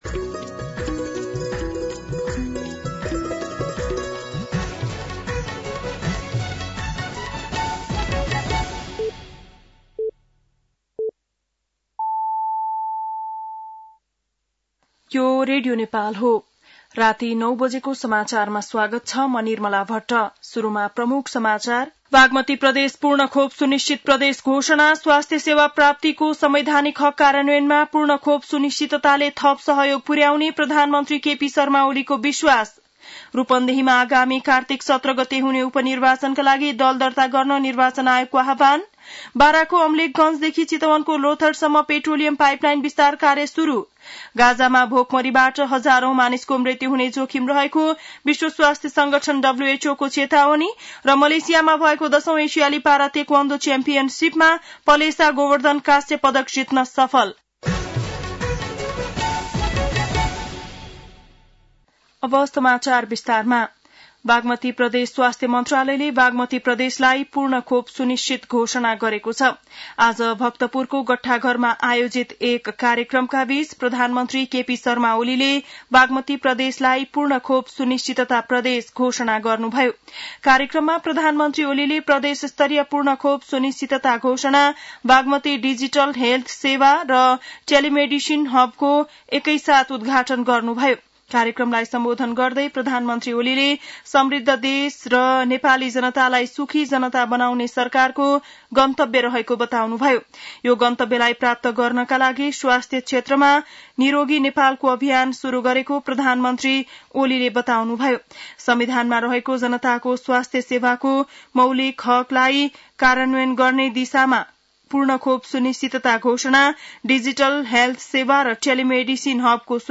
बेलुकी ९ बजेको नेपाली समाचार : १६ साउन , २०८२